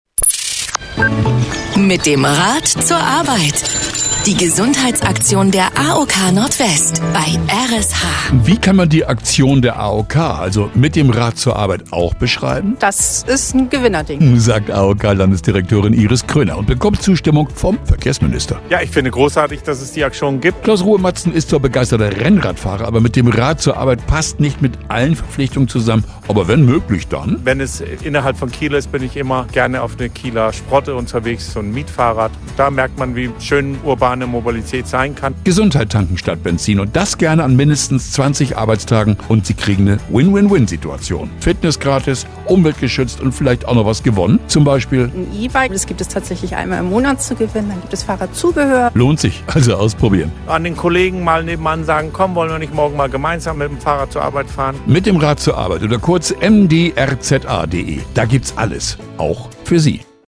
R.SH-Sendebeitrag 3